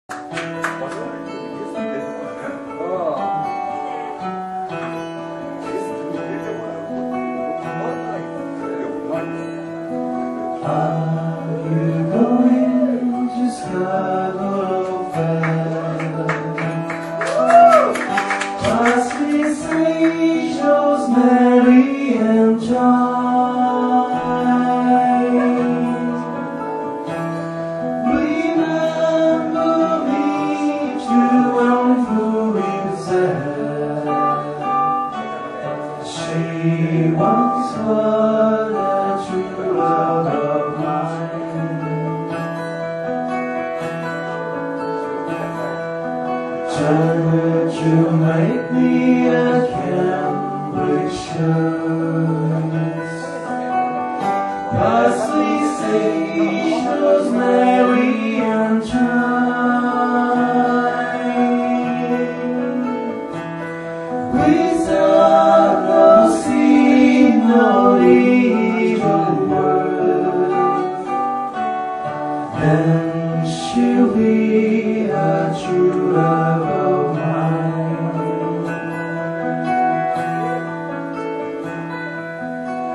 BACK IN TOWN LIVE
下の曲は当日のライブから収録したファーストステージの一部です。